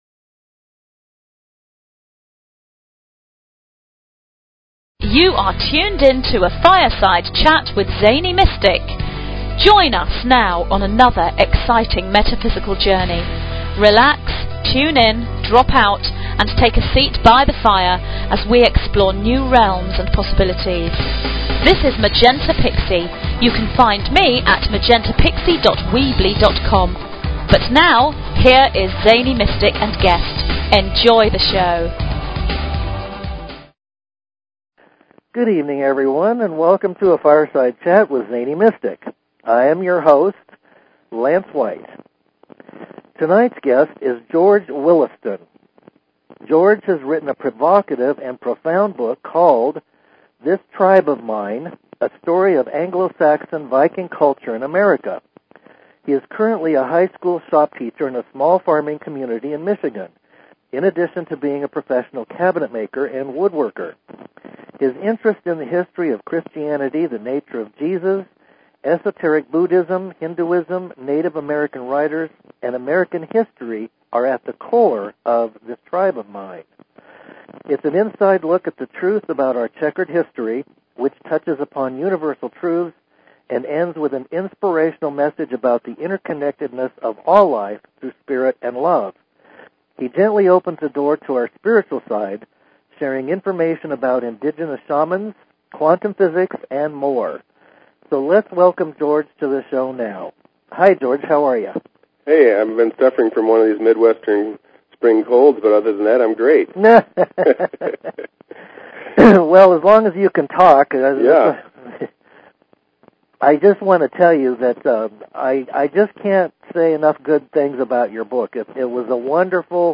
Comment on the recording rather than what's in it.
The connection is "shaky", due to weather.